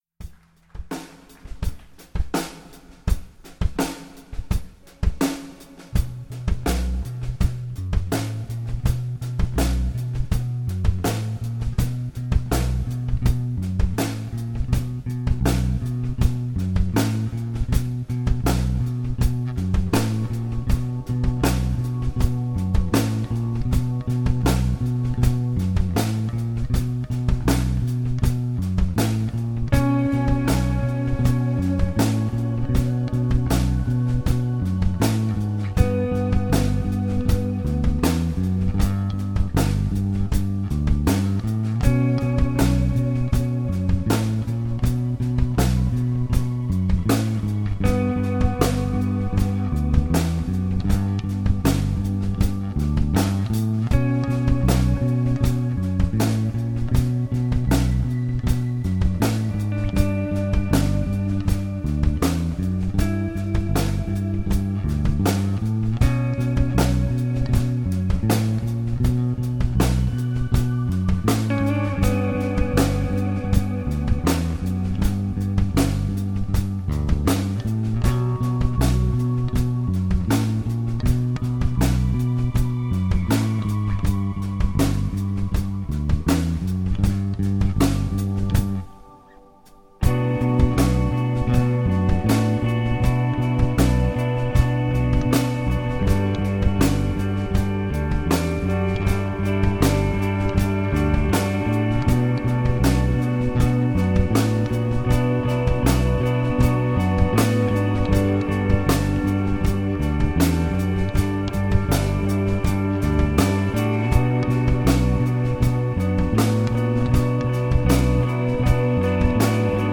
Guitar
bass
drums